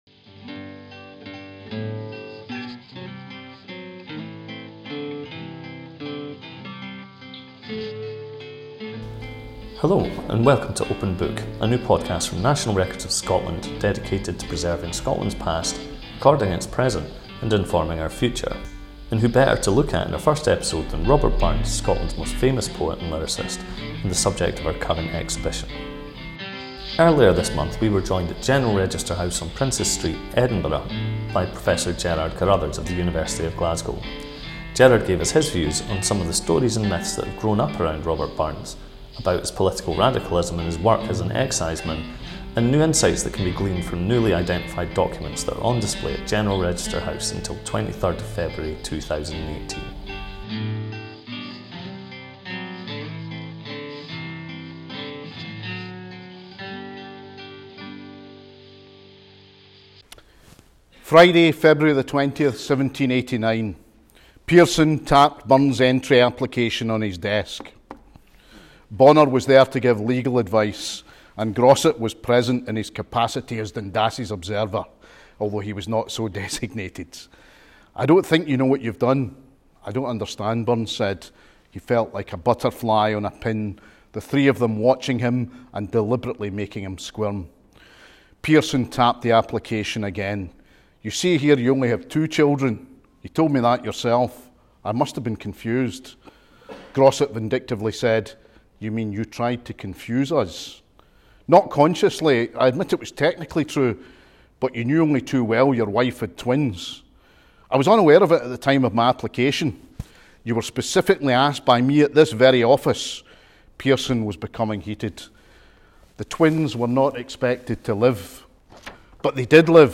This is the first episode of the Open Book Podcast, a new series of talks and discussions from National Records of Scotland dedicated to preserving Scotland’s past, recording the present and informing our future.